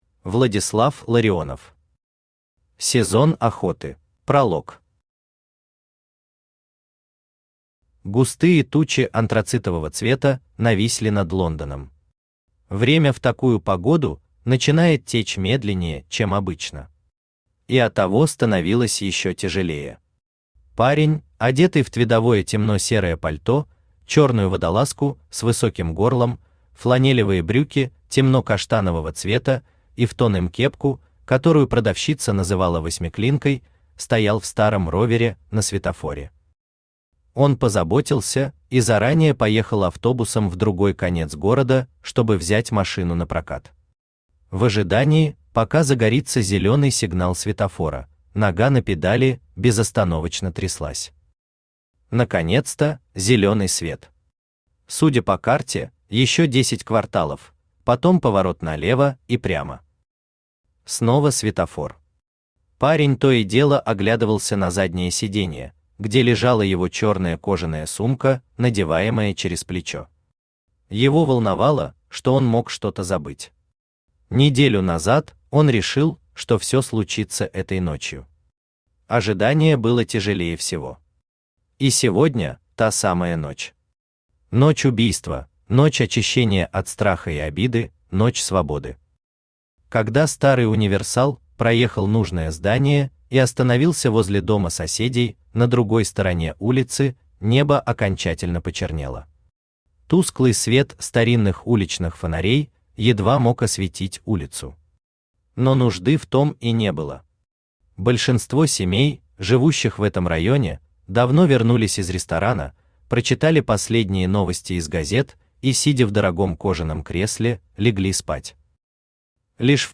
Аудиокнига Сезон охоты | Библиотека аудиокниг
Aудиокнига Сезон охоты Автор Владислав Ларионов Читает аудиокнигу Авточтец ЛитРес.